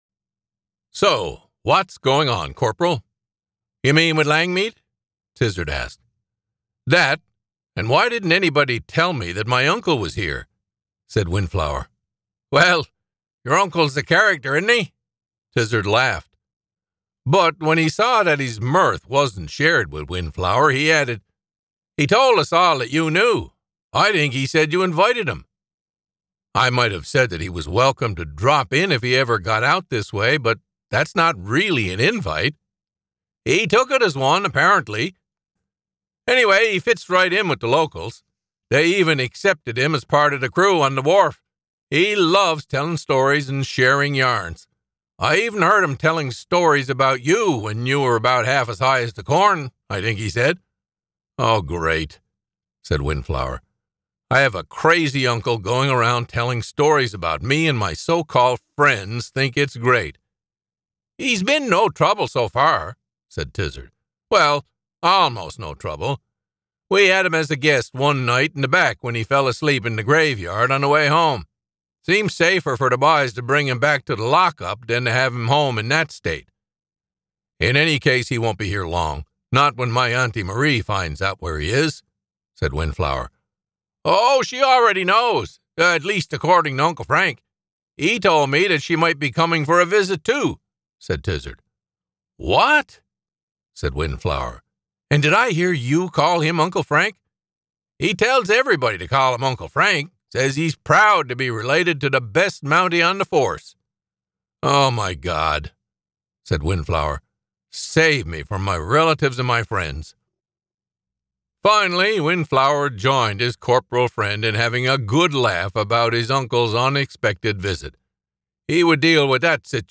New Sgt. Windflower Mystery Audiobook: Beneath the Surface